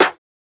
OZ-Snare (Different).wav